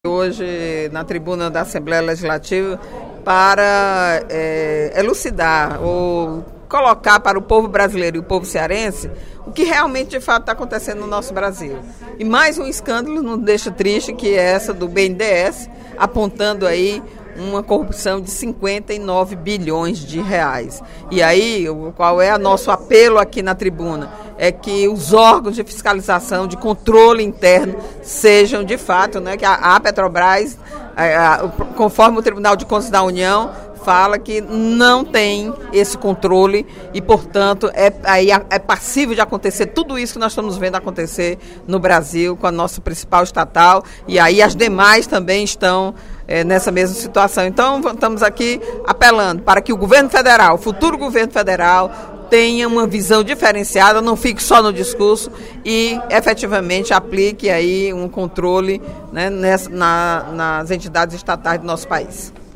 A deputada Eliane Novais (PSB), em pronunciamento no primeiro expediente da sessão plenária desta terça-feira (09/12), fez uma avaliação das investigações realizadas na Petrobras.